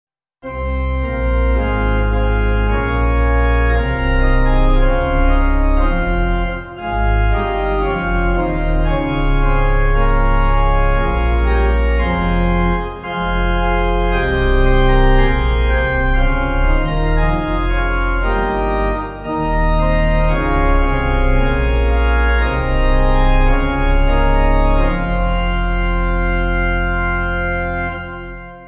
Organ
(CM)   5/Fm